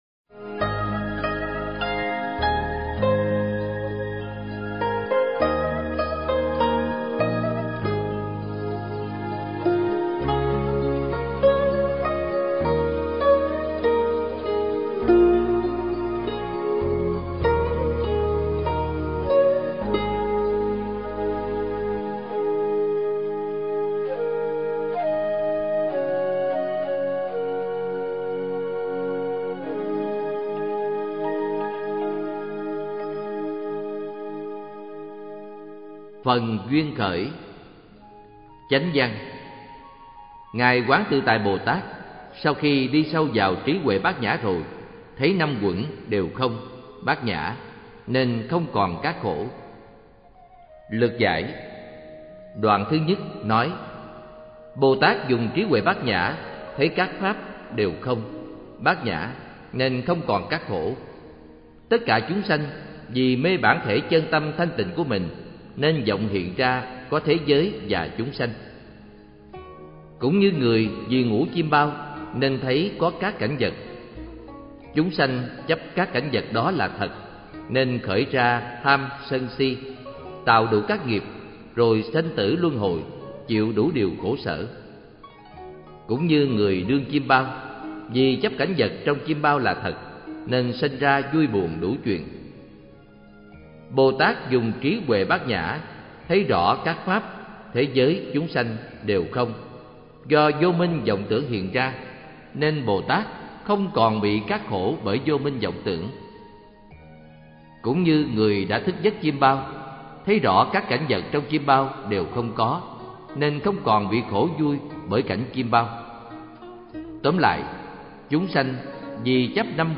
Sách nói